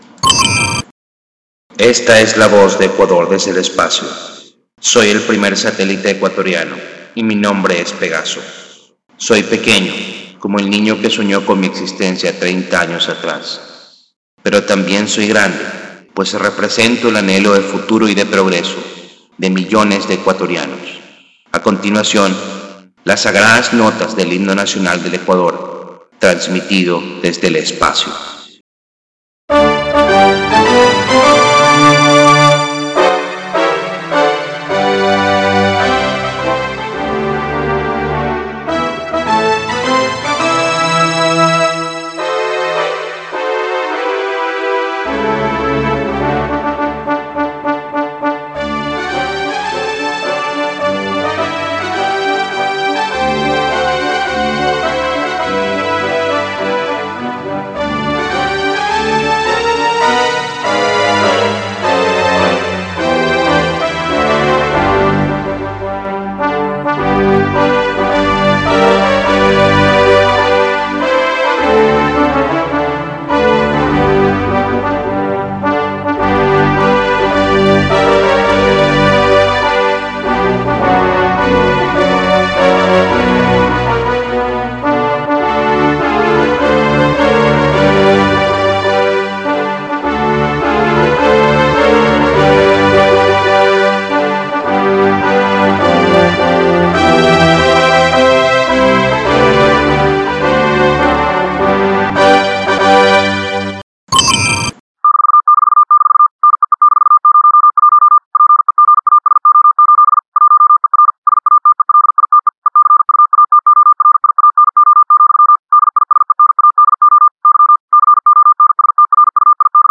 Modülasyon: AMTV – CW – SSTV (çeşitli modlar)
Uydunun uzaydan fotoğraf ve canlı video sinyali göndermenin yanında bir de Ekvador ulusal marşını içeren sürekli işaret göndereceğini yukarıda belirtmiştim. Yayınlarında kullanılacak ses, video/SSTV örnek sinyallarini dilerseniz